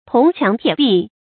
注音：ㄊㄨㄙˊ ㄑㄧㄤˊ ㄊㄧㄝ ˇ ㄅㄧˋ
銅墻鐵壁的讀法